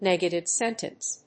音節nègative séntence